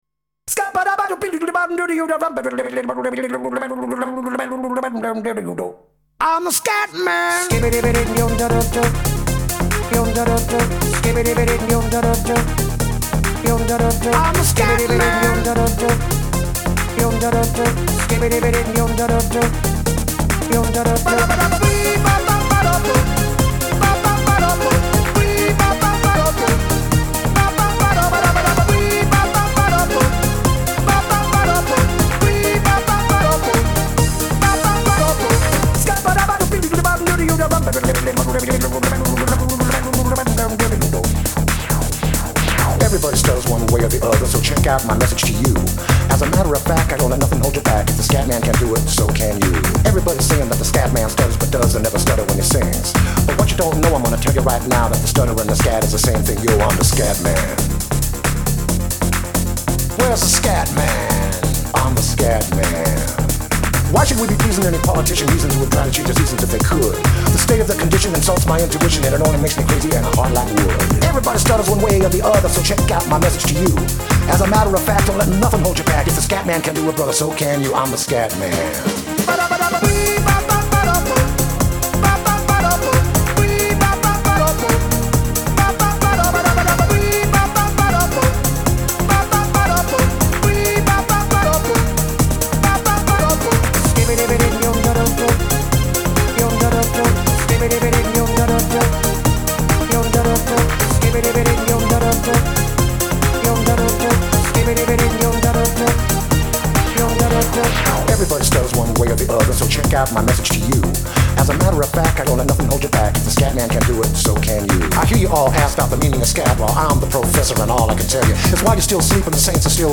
EDM 90er